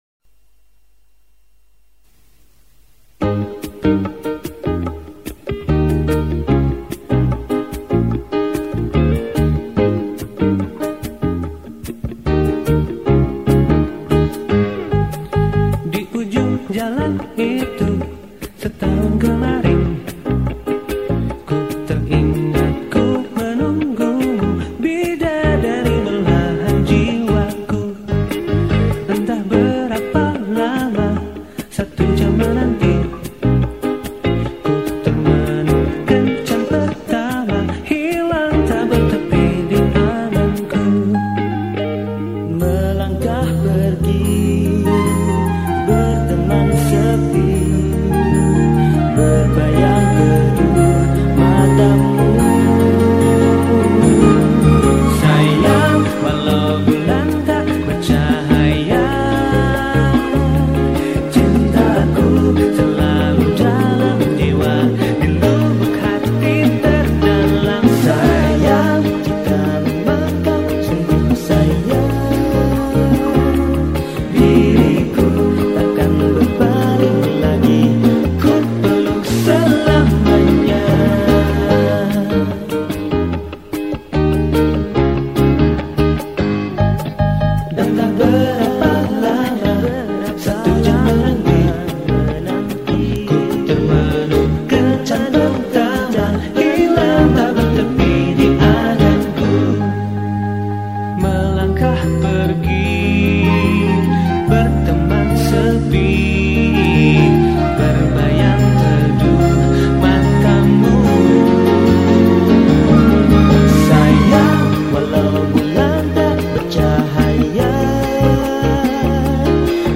Genre Musik                                : Jazz dan Pop